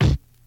Kick (Dusty).wav